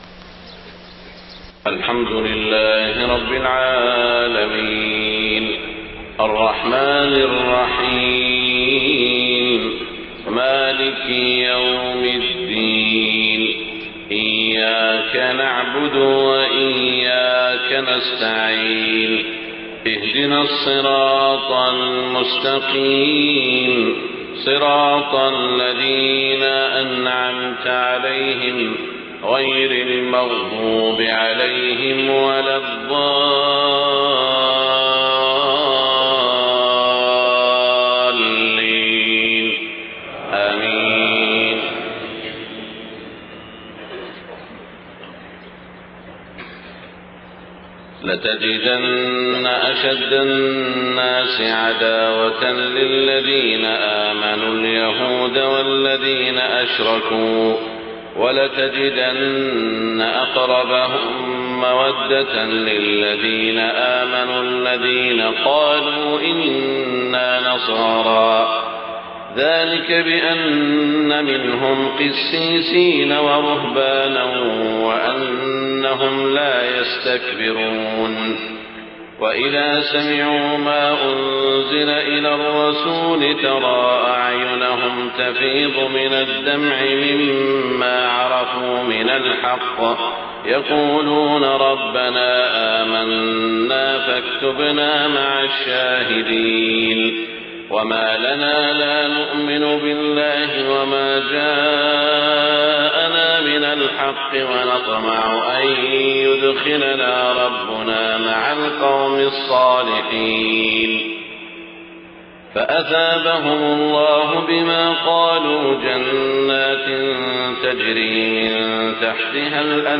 صلاة الفجر 26 ذو الحجة 1429هـ من سورة المائدة > 1429 🕋 > الفروض - تلاوات الحرمين